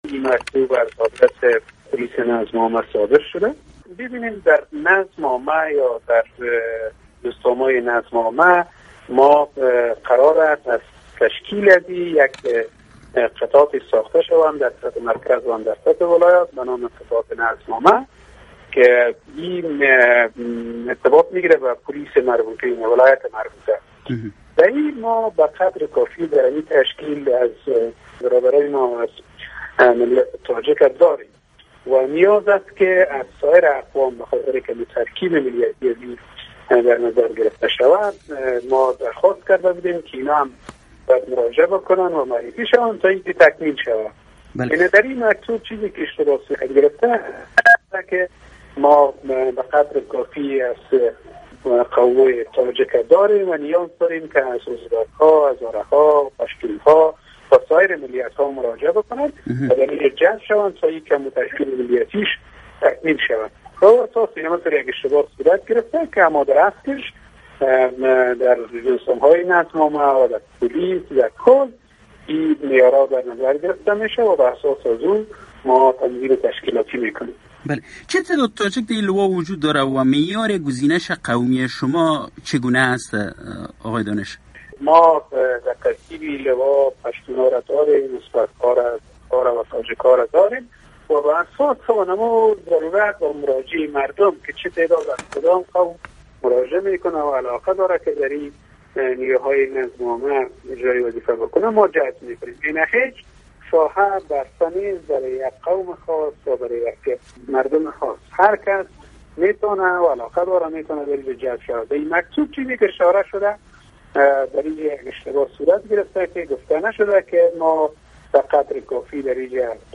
شرح کامل مصاحبه